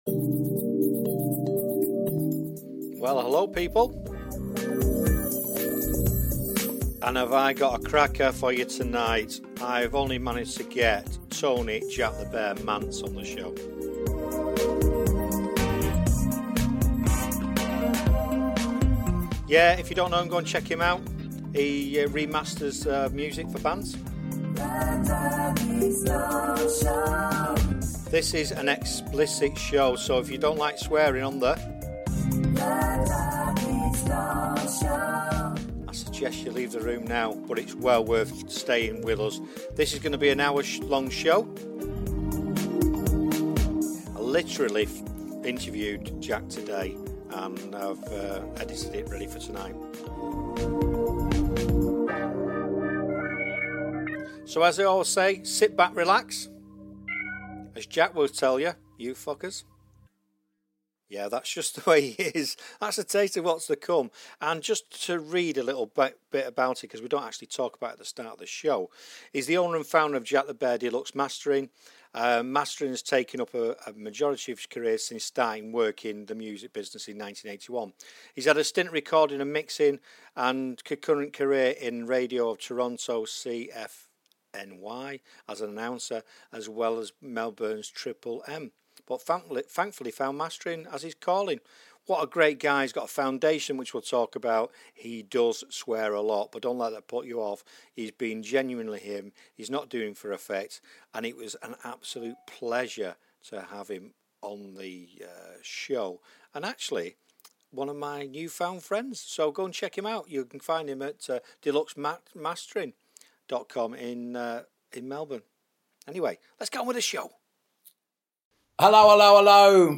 An Explicit interview
Loved interning him , if you don't like swearing please don't list lots of it